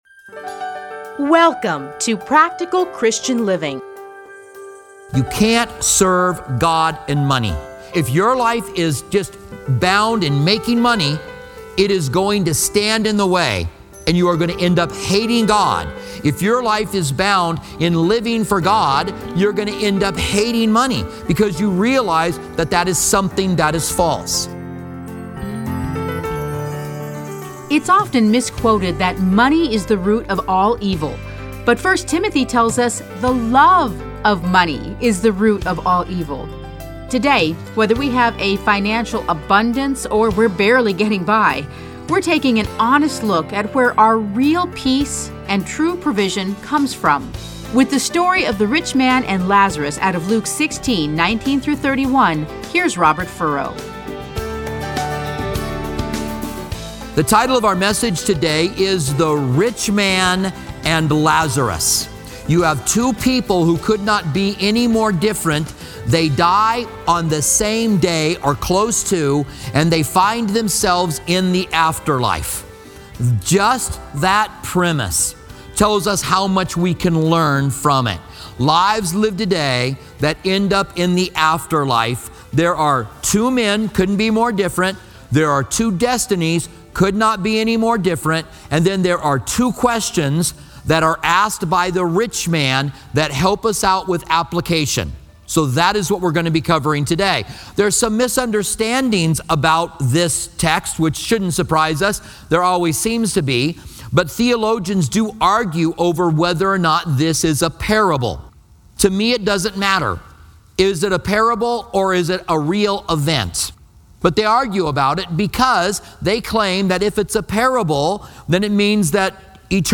Listen to a teaching from Luke 16:19-31.